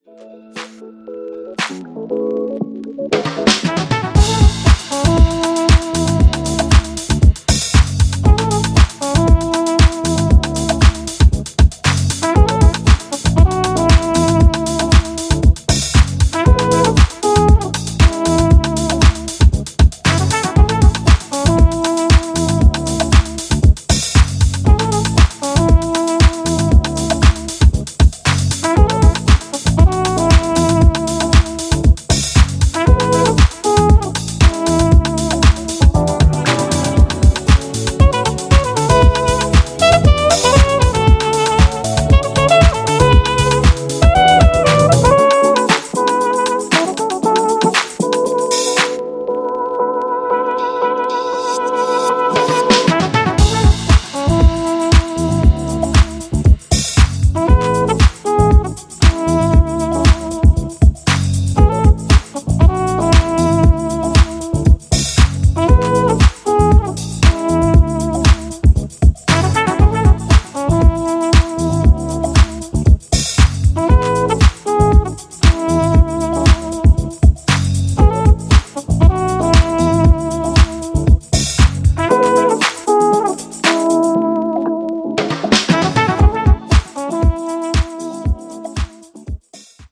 ジャンル(スタイル) NU DISCO / DISCO HOUSE / DEEP HOUSE